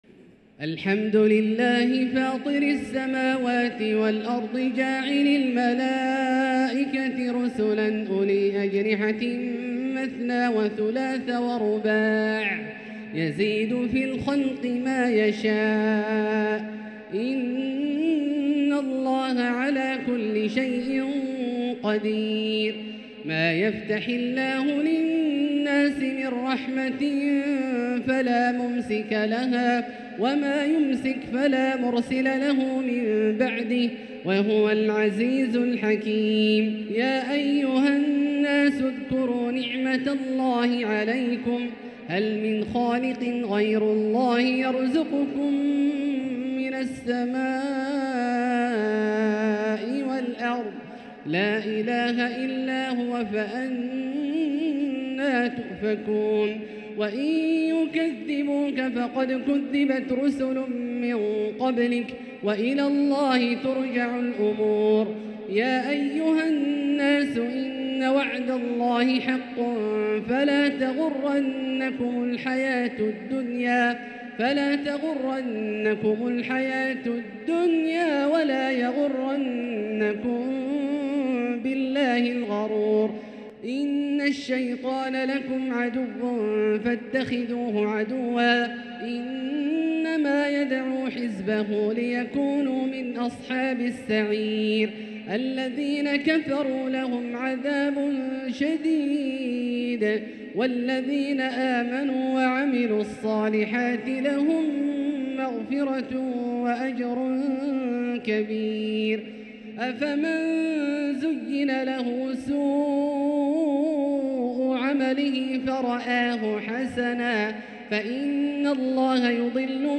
تلاوة بديعة تفيض بالجمال لـ سورة فاطر كاملة للشيخ د. عبدالله الجهني من المسجد الحرام | Surat Fatir > تصوير مرئي للسور الكاملة من المسجد الحرام 🕋 > المزيد - تلاوات عبدالله الجهني